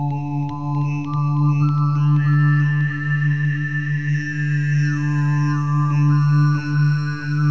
Dadurch entsteht der Höreindruck des zweistimmigen Singens.
Demo für westliches Obertonsingen bei langsamer Internet-Verbindung
obertoene-mini-demo.mp3